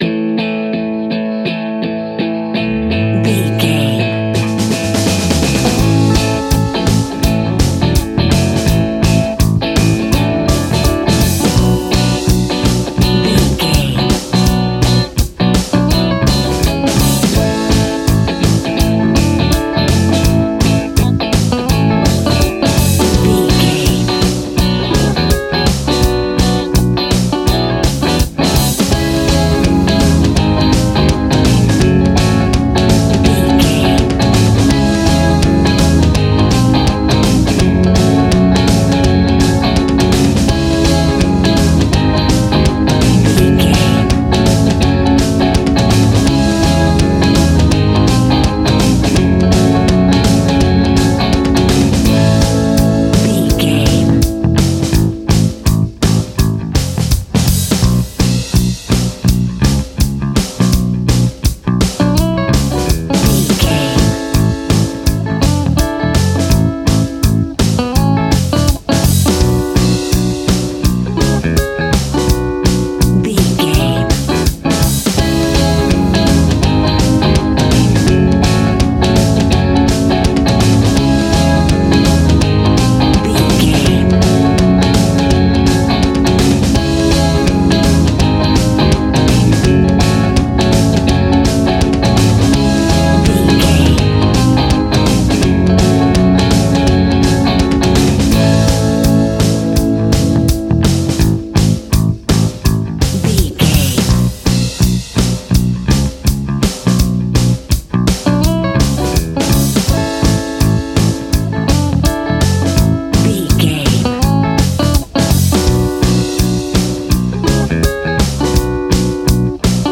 Aeolian/Minor
D♭
groovy
happy
electric guitar
bass guitar
drums
piano
organ